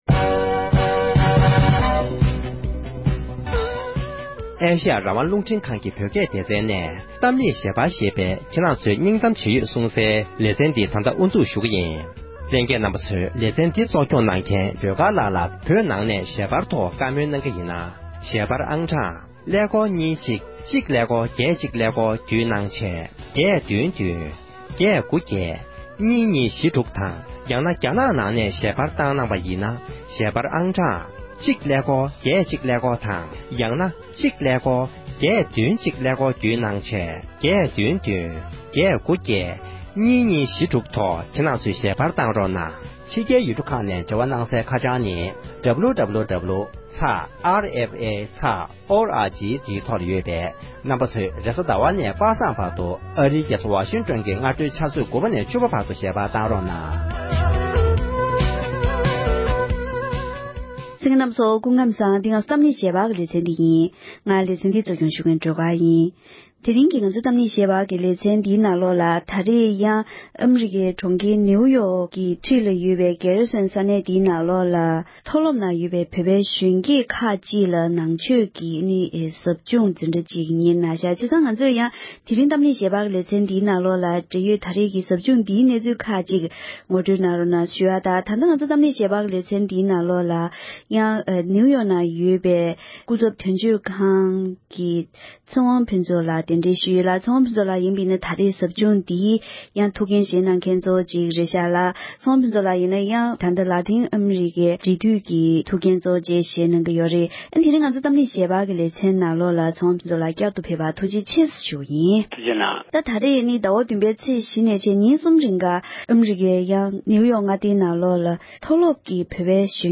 བཀའ་འདྲི་